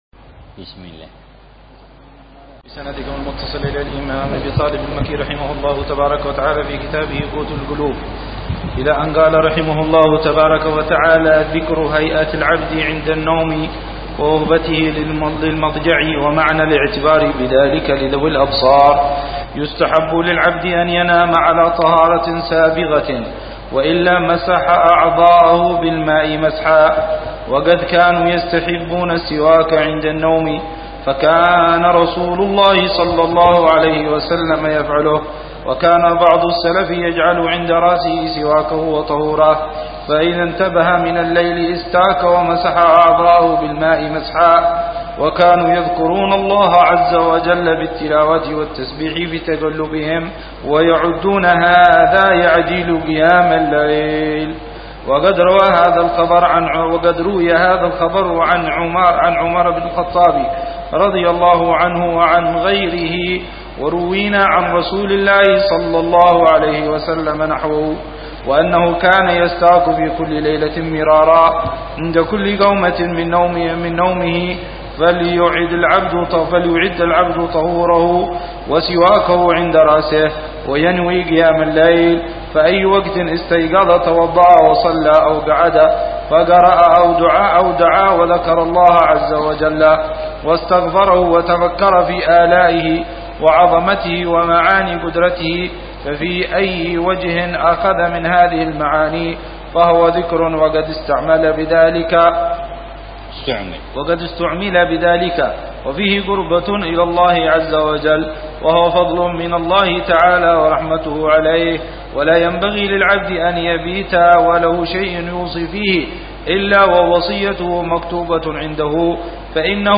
شرح كتاب قوت القلوب - الدرس السابع
قراءة بتأمل وشرح لمعاني كتاب قوت القلوب للشيخ: أبي طالب المكي ضمن دروس الدورة التعليمية الخامسة عشرة بدار المصطفى 1430هجرية.